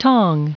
Prononciation du mot tong en anglais (fichier audio)